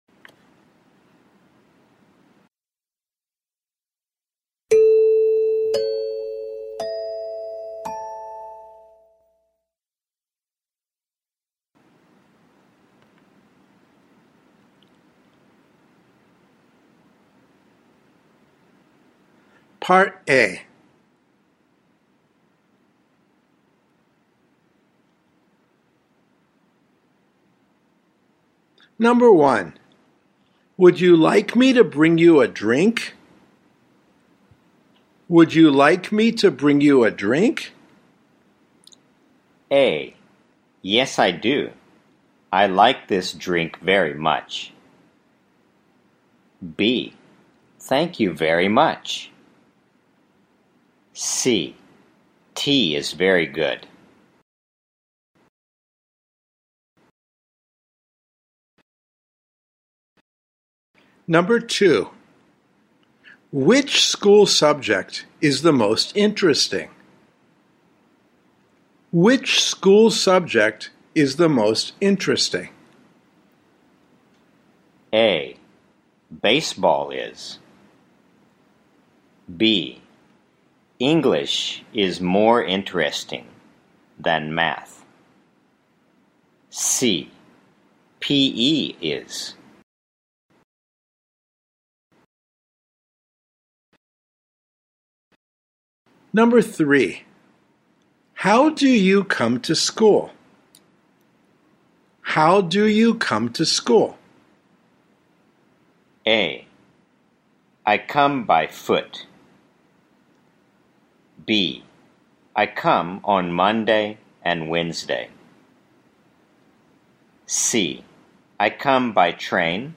2025年度（令和7年度）梅花高等学校　英語リスニング入試問題 ※その他入試問題については、資料請求ページよりお問い合わせください。